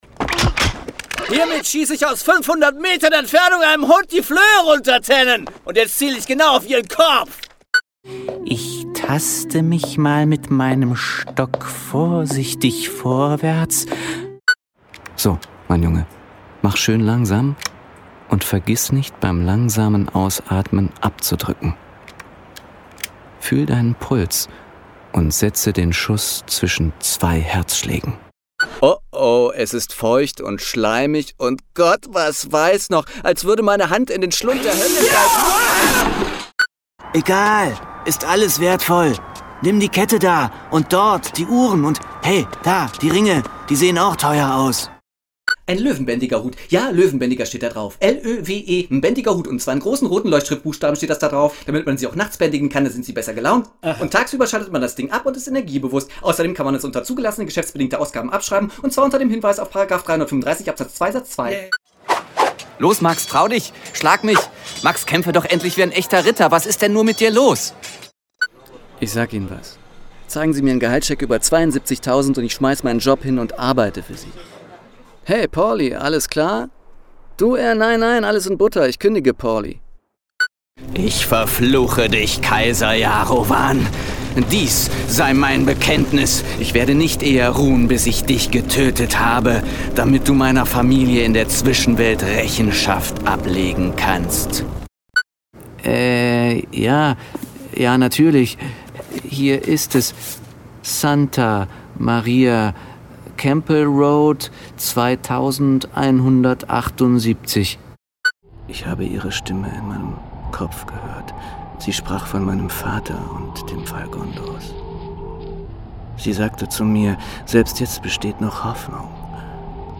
Rollen | Stimmschauspiel – Verschiedene